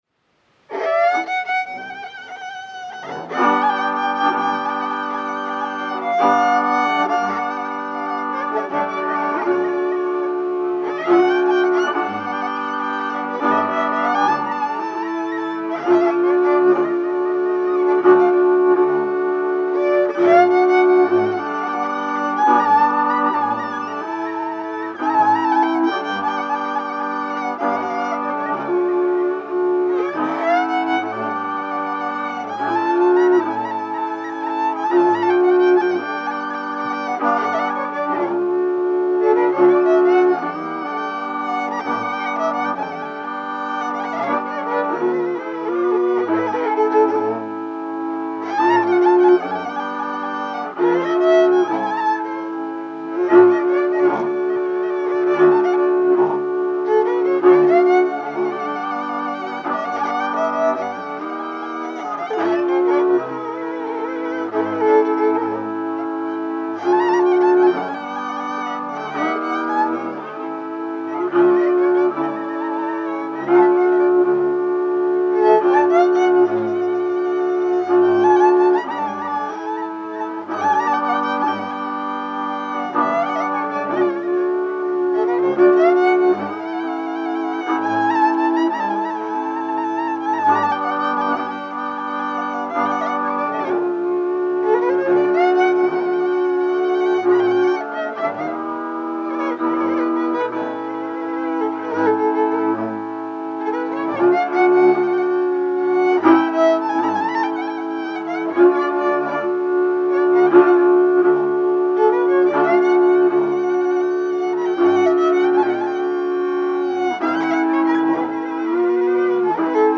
Műfaj: Régies párostánc: Lassú cigánytánc
Előadásmód: Zenekar
Helység: Visa